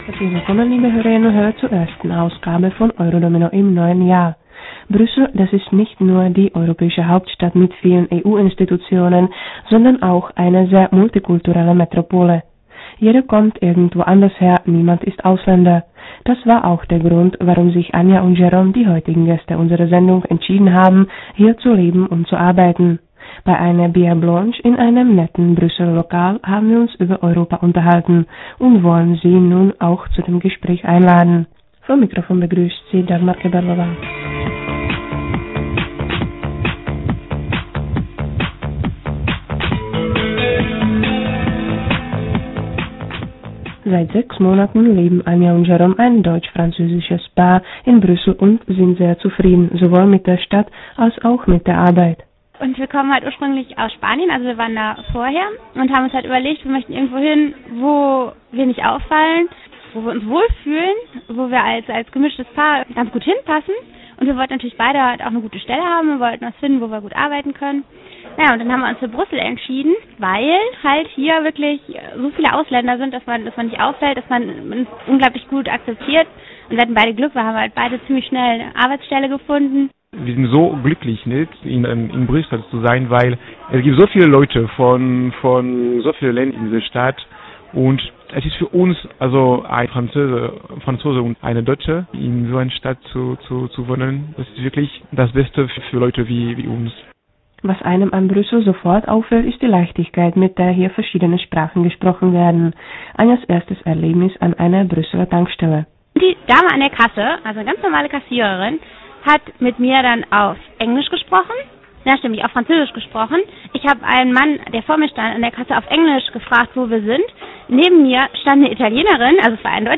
Bei einer "biere blanche" in einem netten Brüsseler Lokal haben wir uns über Europa unterhalten und wollen Sie nun auch zu dem Gespräch einladen.